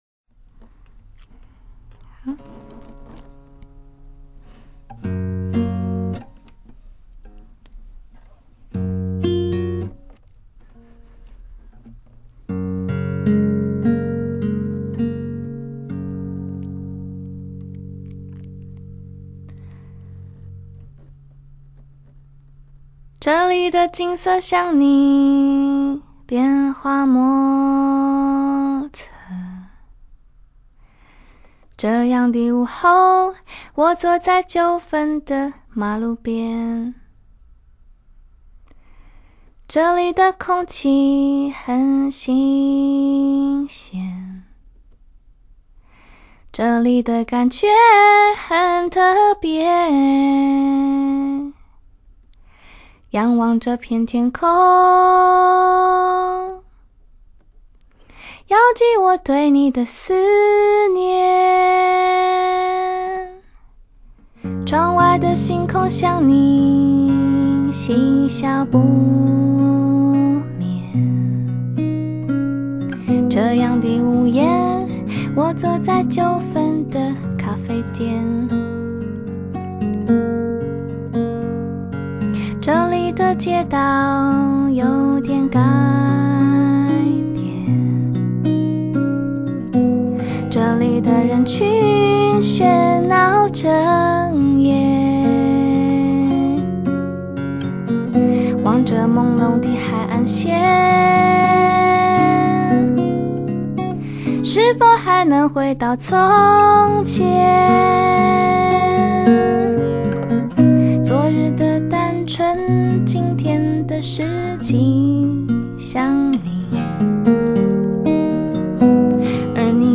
她的声音像一把软的刀子 无声无息划过你 等你回神的时候 已经伤到最深的地方 幽微的痛着